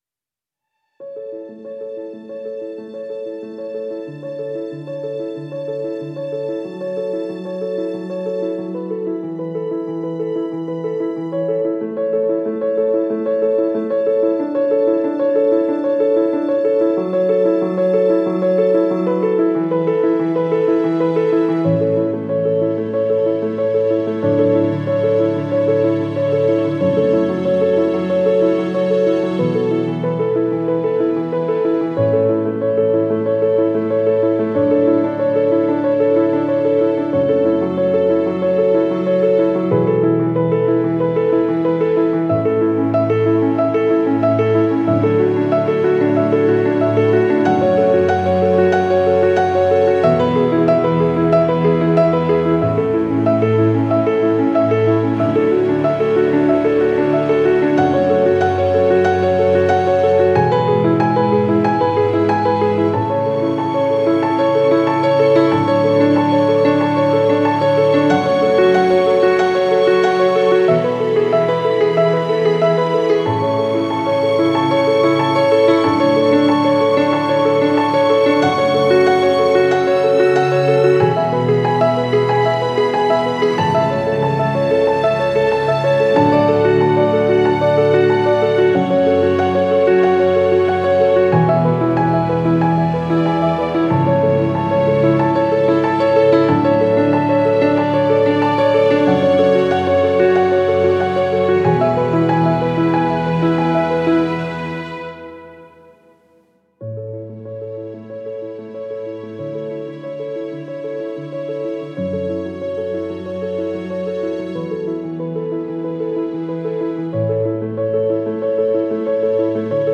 Genre: filmscore, classical.